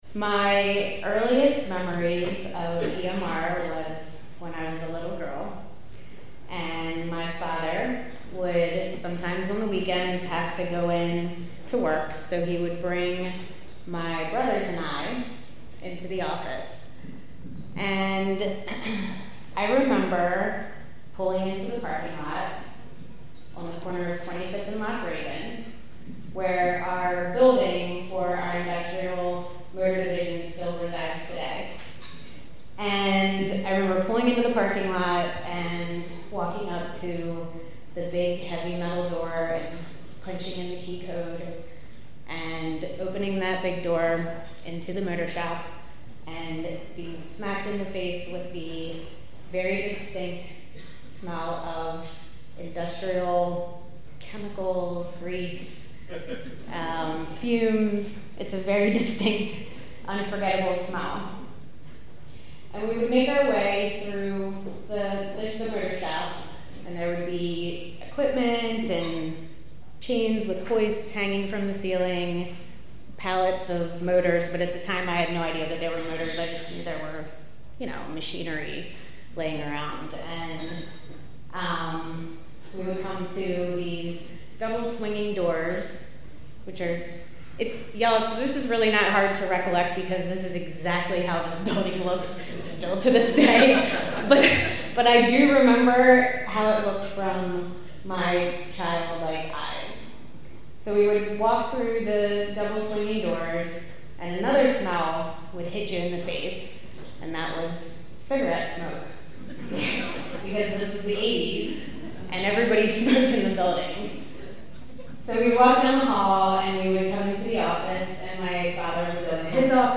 Fundraiser for the Baltimore Museum of Industry